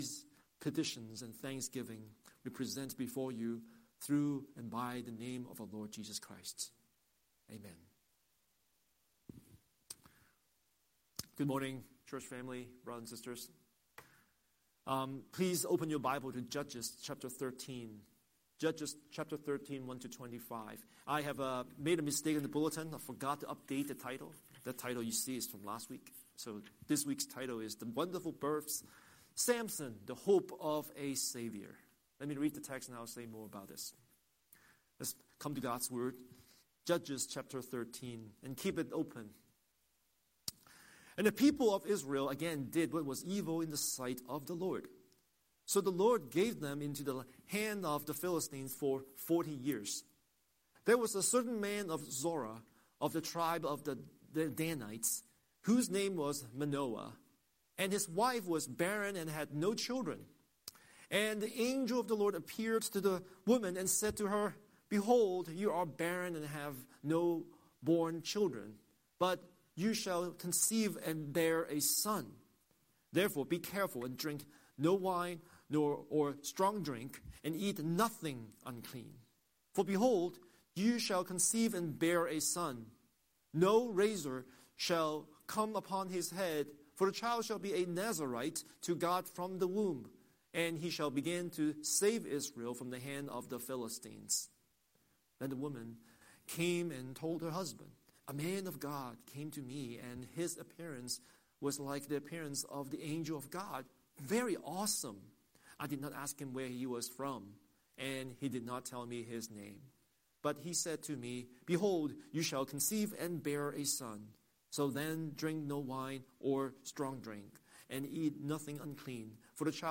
Scripture: Judges 13:1–25 Series: Sunday Sermon